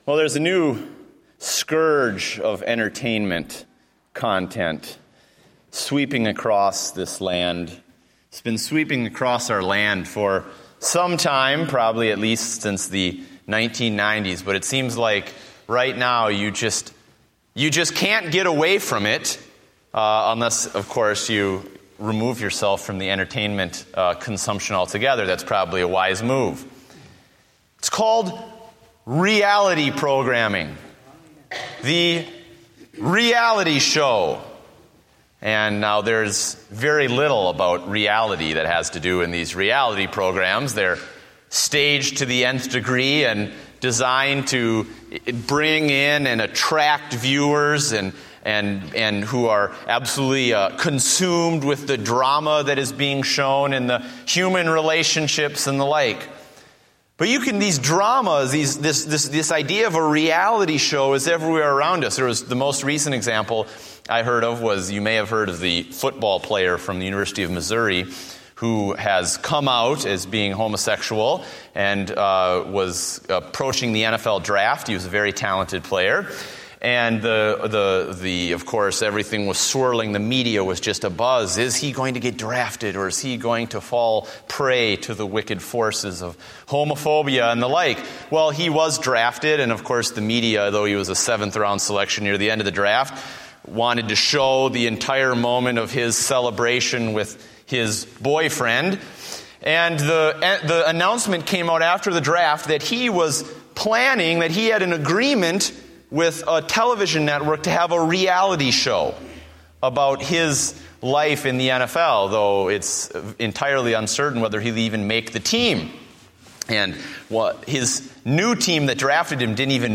Date: May 18, 2014 (Evening Service)